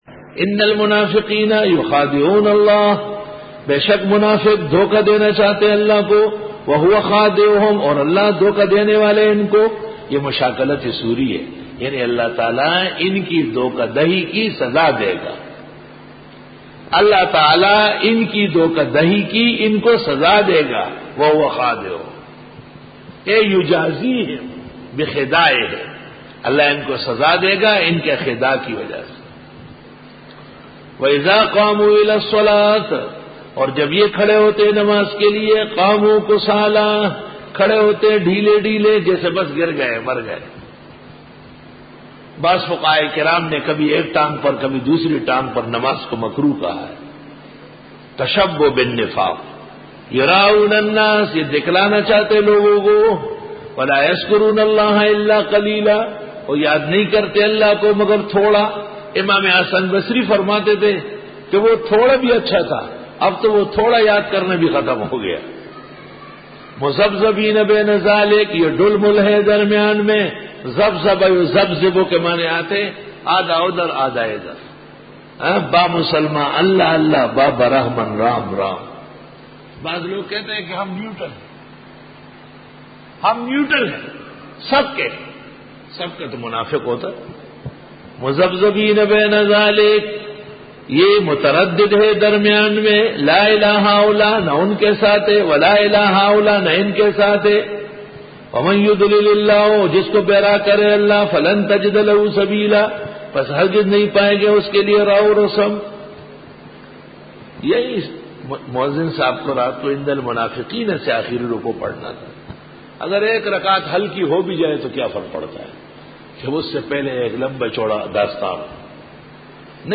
Dora-e-Tafseer 2007